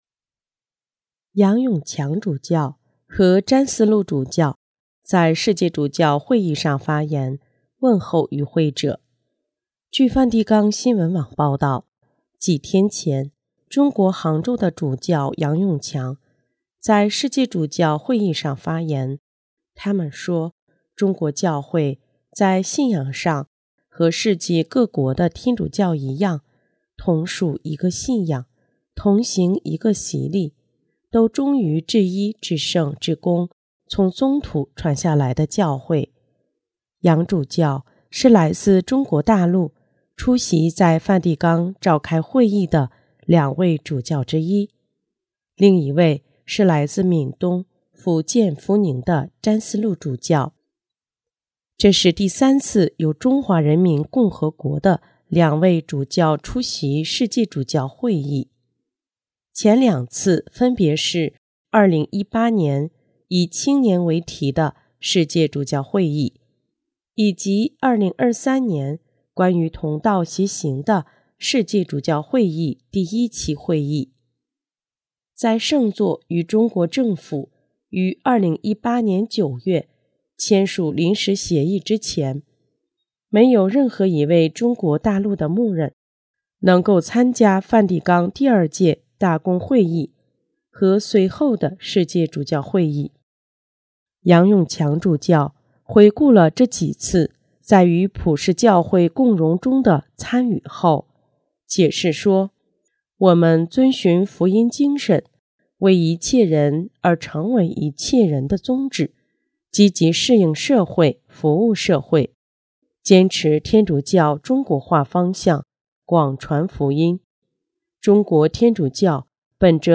杨永强主教和詹思禄主教在世界主教会议上发言，问候与会者。